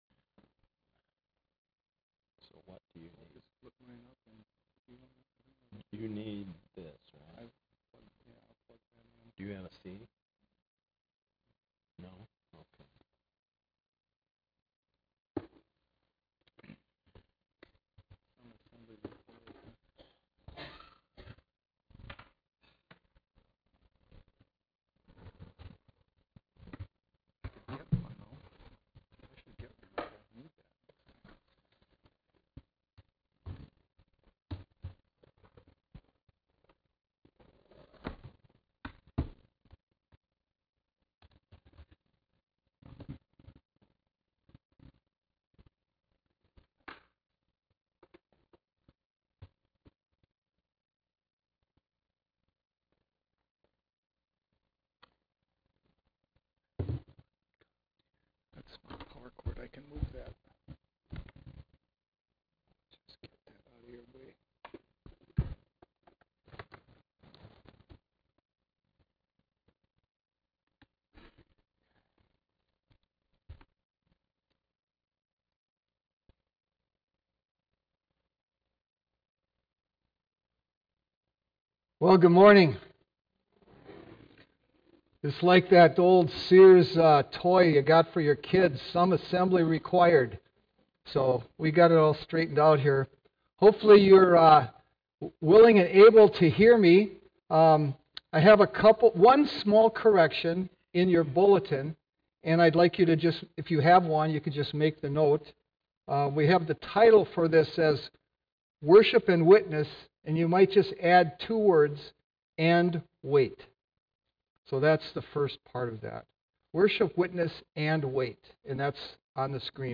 Sermons | Lake Phalen Community Church